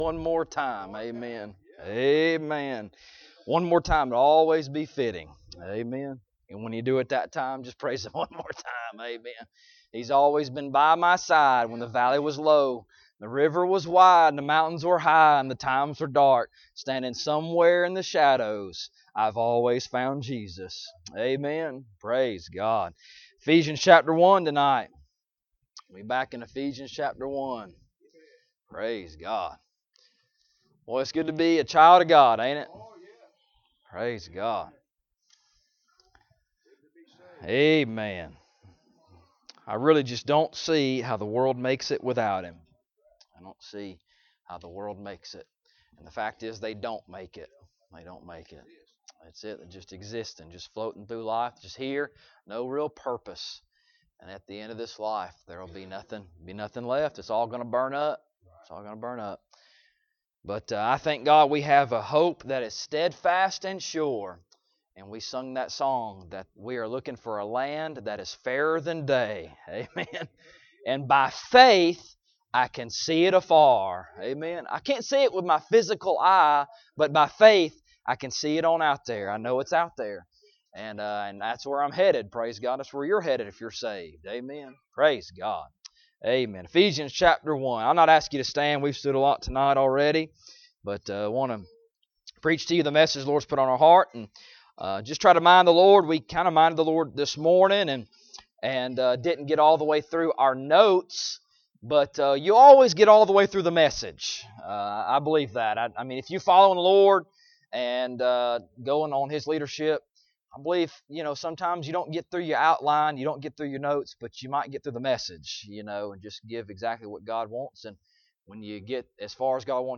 Ephesians 1:13-14 Service Type: Sunday Evening Topics